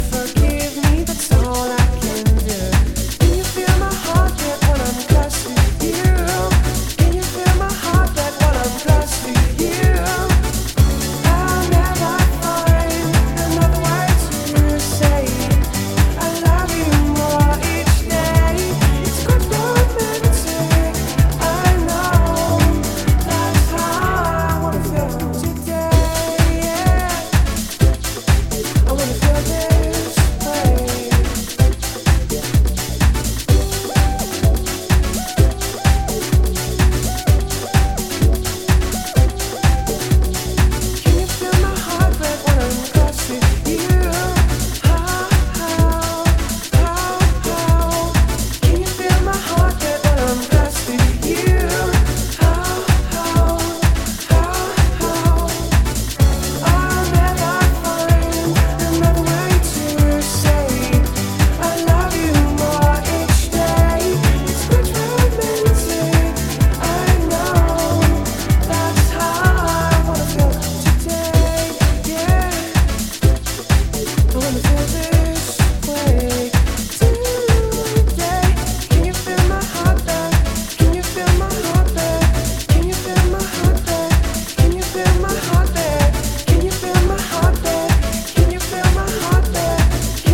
原曲のタイムレスな魅力とアーリーハウス・マナーのリミックスが絶妙にマッチした、プロモオンリーの12インチ。
DEEP HOUSE / EARLY HOUSE